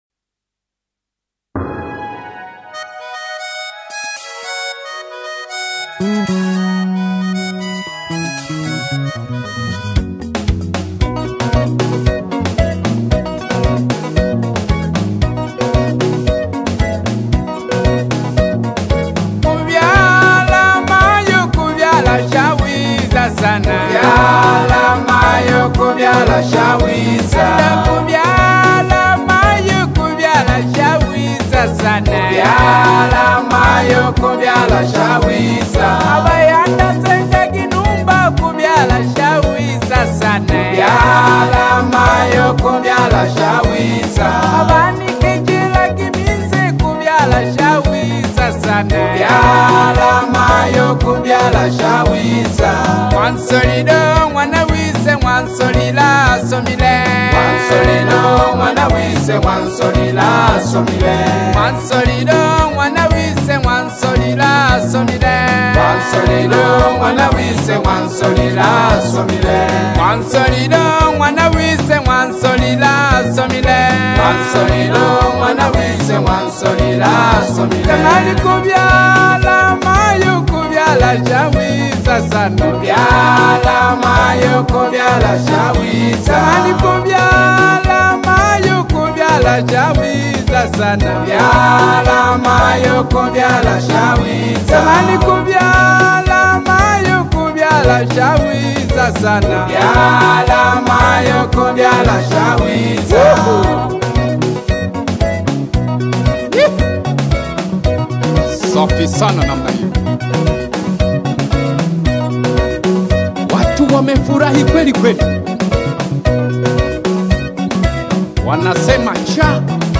Ngoja nikuongezee na wimbo mwingine wa harusi za Wanyamwezi na Wasukuma.